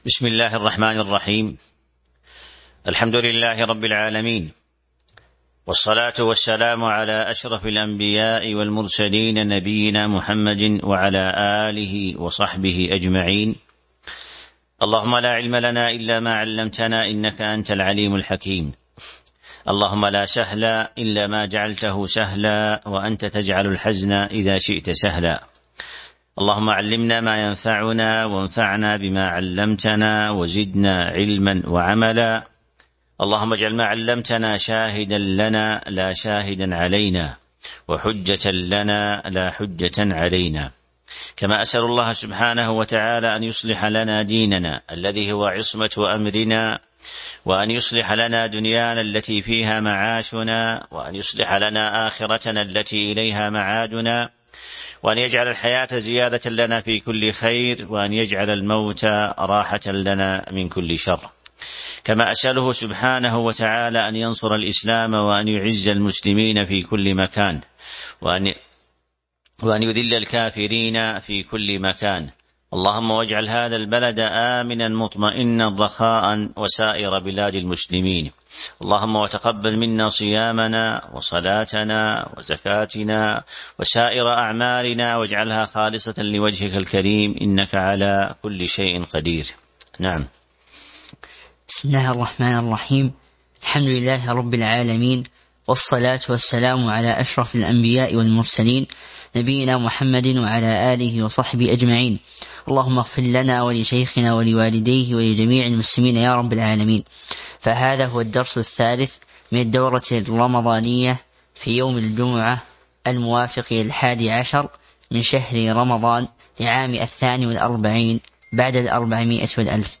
تاريخ النشر ١١ رمضان ١٤٤٢ المكان: المسجد النبوي الشيخ